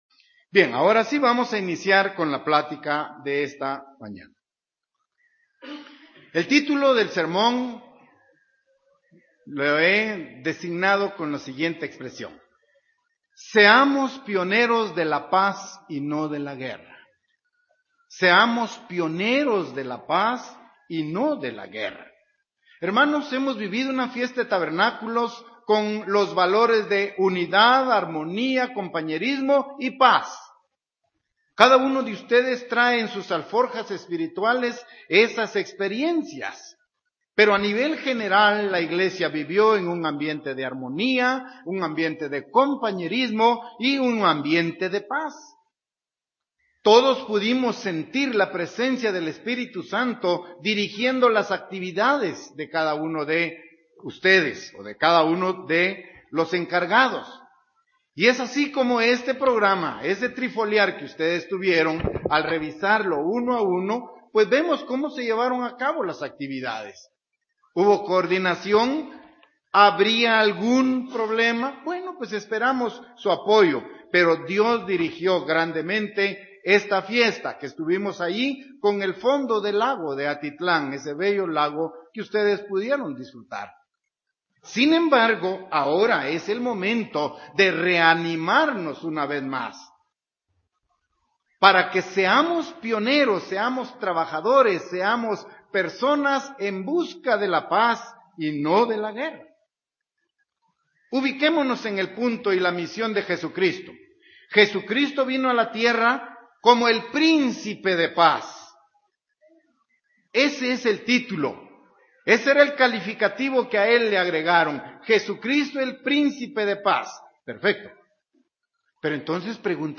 Given in Ciudad de Guatemala